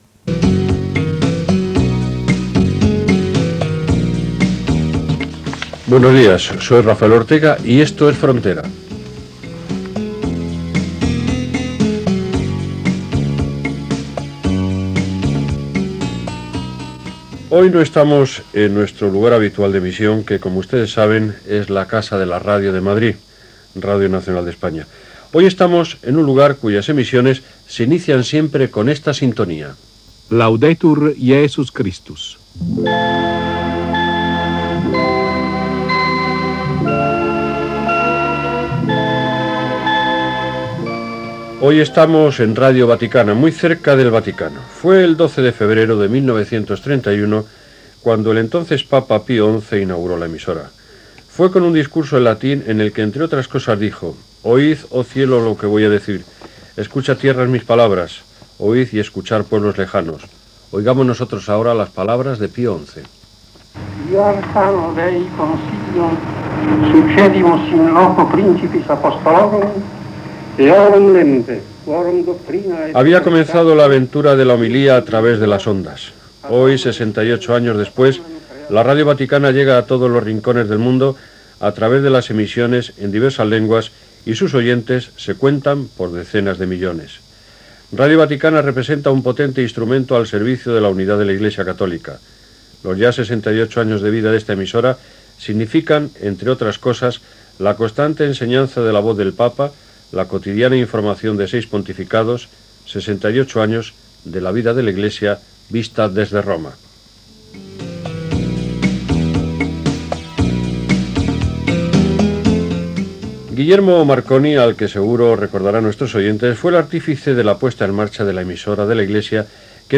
Espai realitzat als estudis de Ràdio Vaticà sobre la seva història